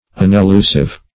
unelusive - definition of unelusive - synonyms, pronunciation, spelling from Free Dictionary